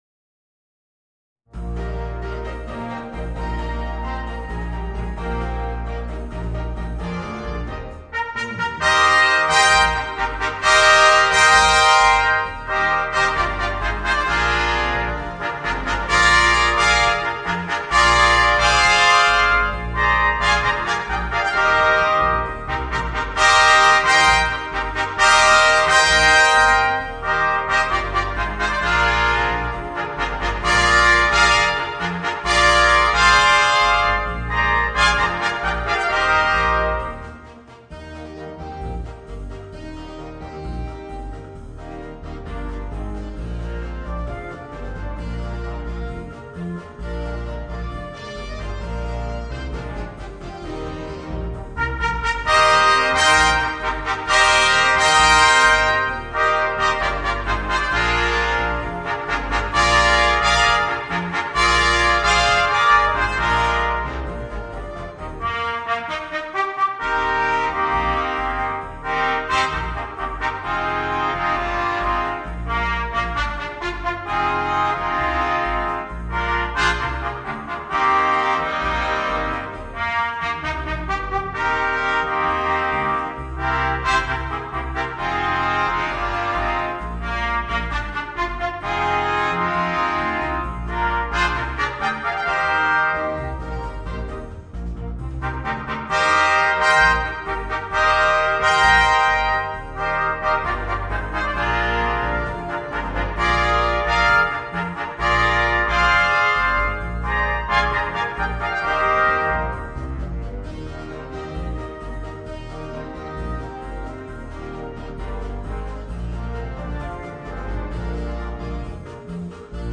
Voicing: 4 Trumpets and Concert Band